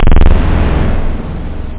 tb303-samples-1 / 303.hall-1